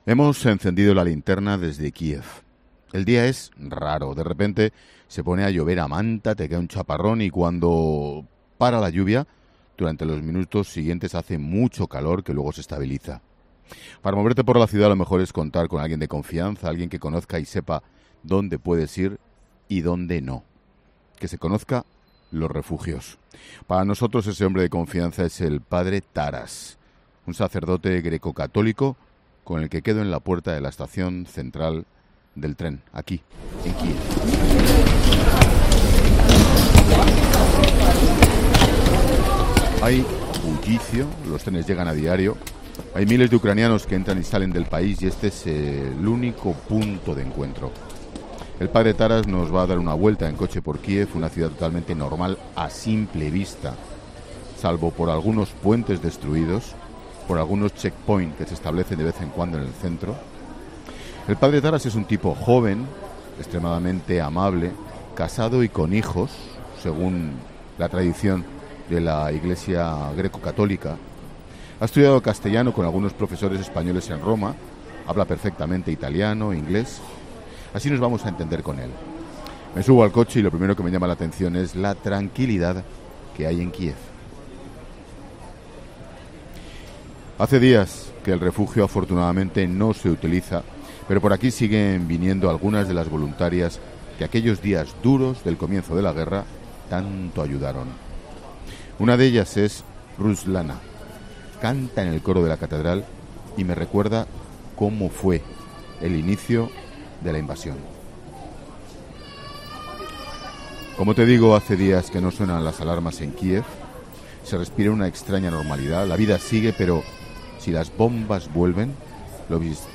Ángel Expósito ha encendido La Linterna desde Kiev por segundo día consecutivo.